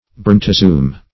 Brontozoum \Bron`to*zo"um\, n. [NL., fr. Gr.